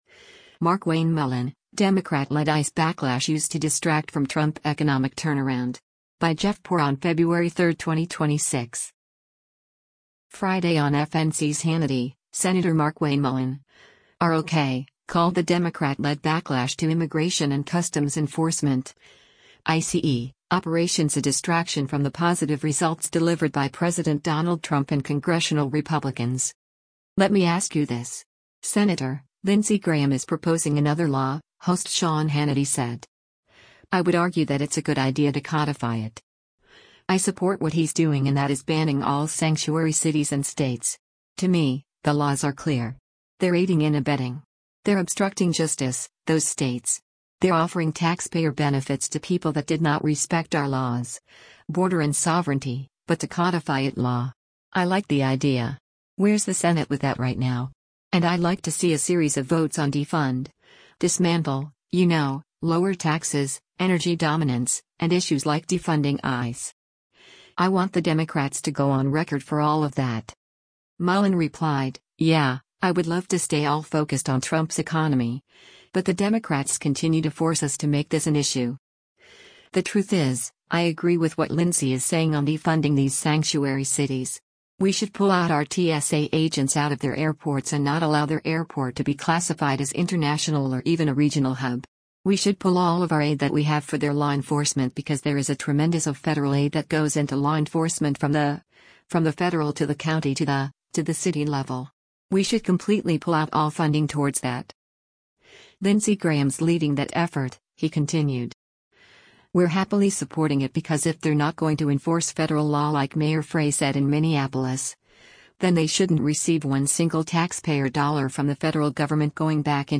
Friday on FNC’s “Hannity,” Sen. Markwayne Mullin (R-OK) called the Democrat-led backlash to Immigration and Customs Enforcement (ICE) operations a distraction from the positive results delivered by President Donald Trump and congressional Republicans.